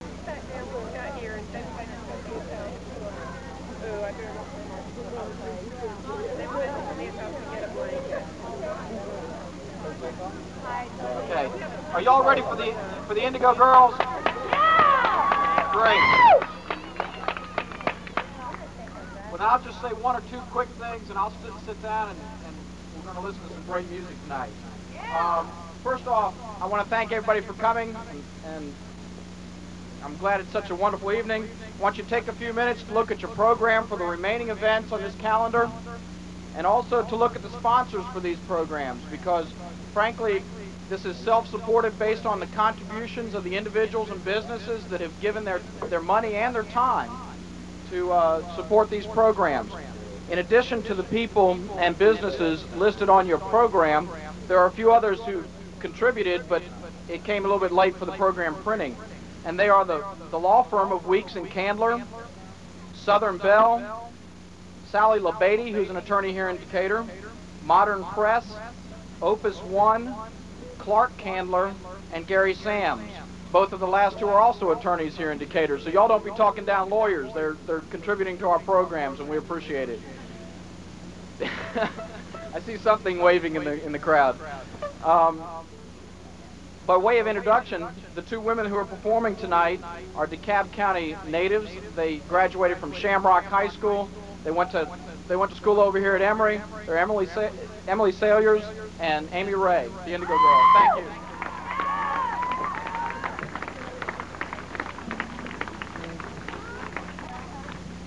(acoustic duo show)
01. introduction (1:48)